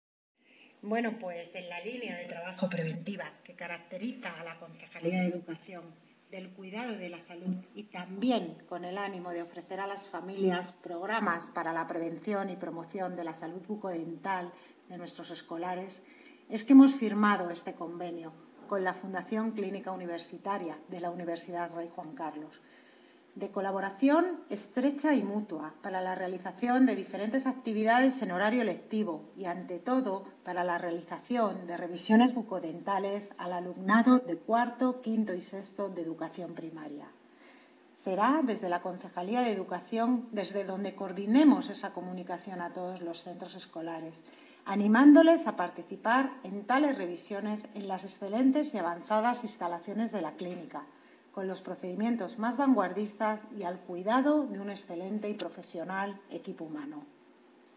Isabel Cruceta, concejal de Educación - Firma convenio bucodental